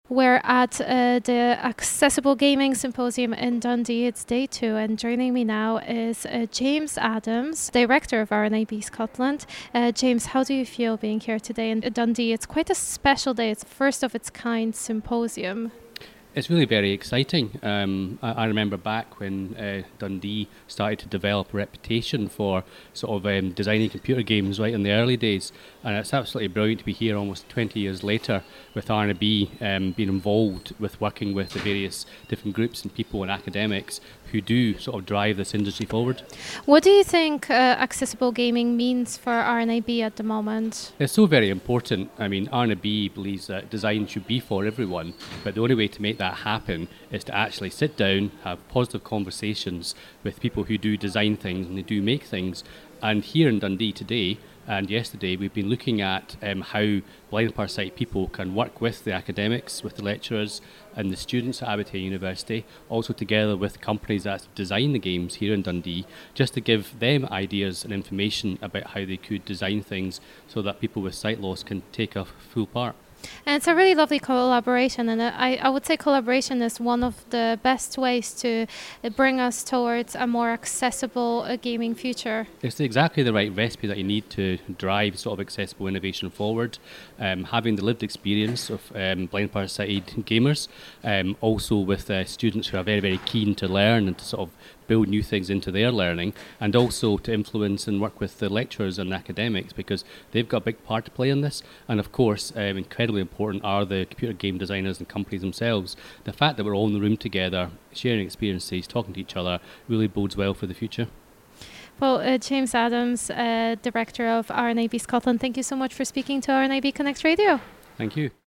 Accessible Gaming Symposium 2022 Interview